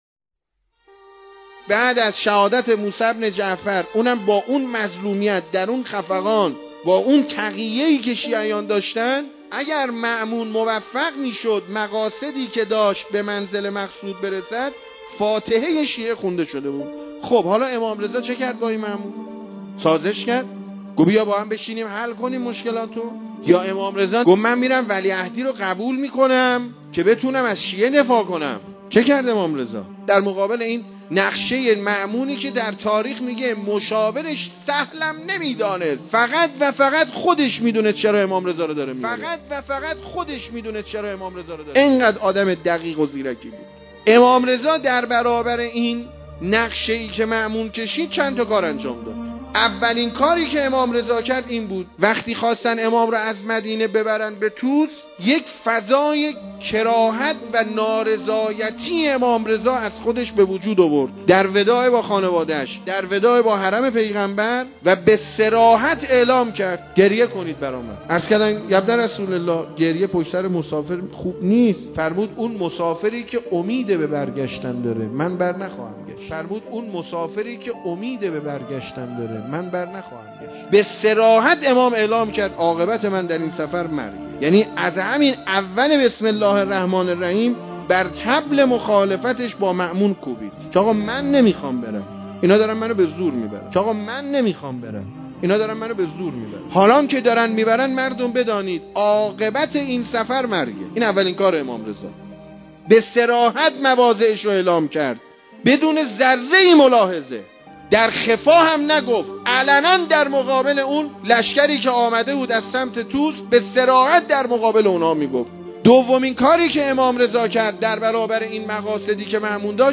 منبر